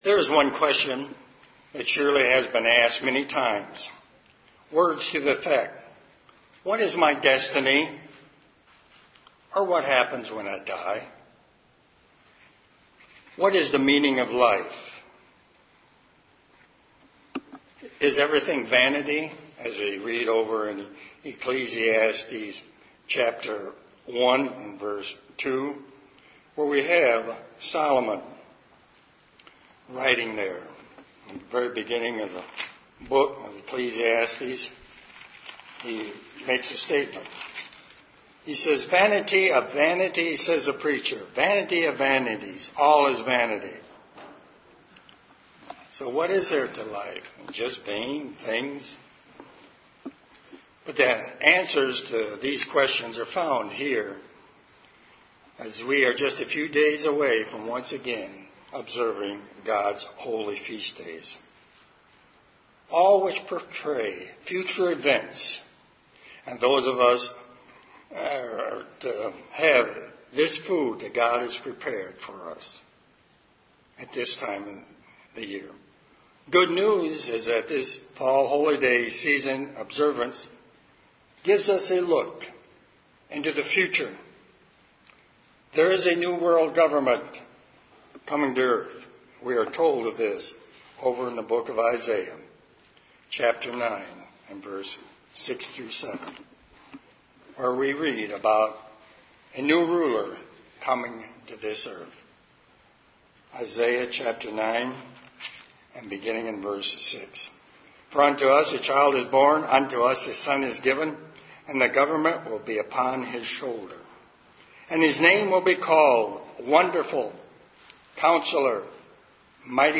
Given in Detroit, MI
UCG Sermon Studying the bible?